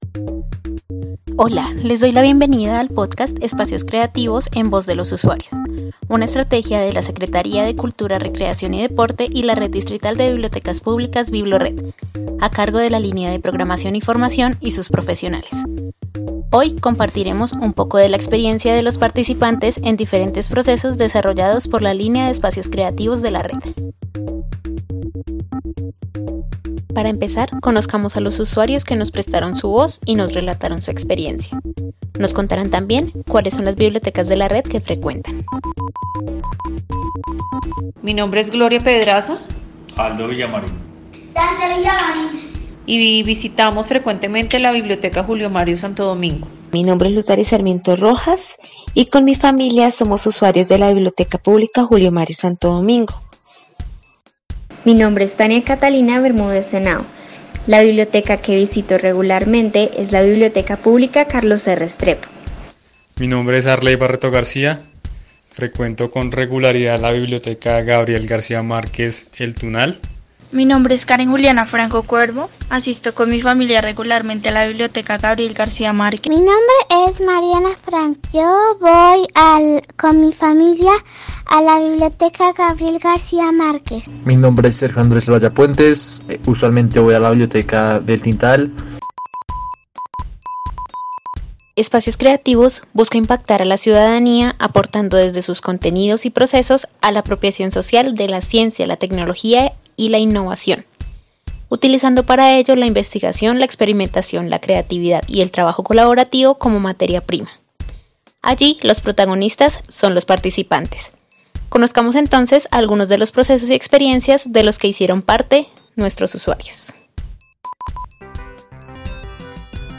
Reúne la voz de usuarios de distintas bibliotecas de la Red, participantes en diferentes procesos de Espacios Creativos.